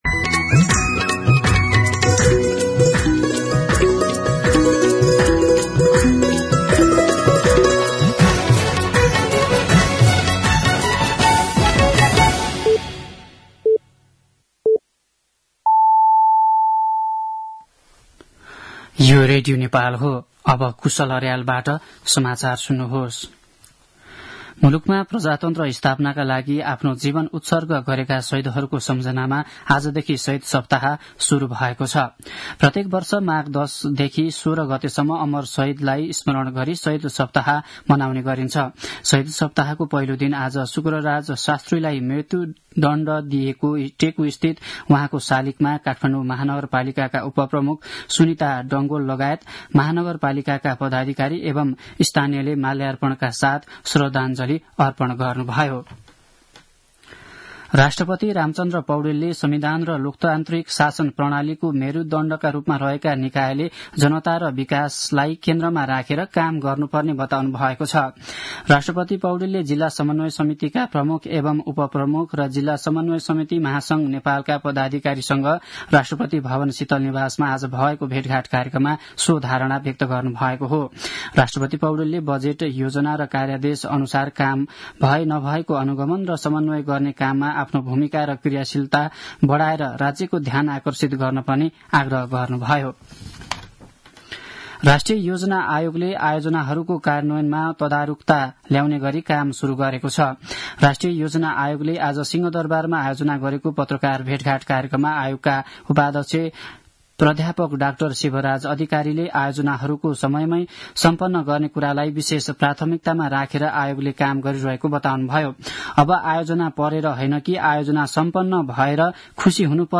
दिउँसो ४ बजेको नेपाली समाचार : ११ माघ , २०८१
4-pm-nepali-news-6.mp3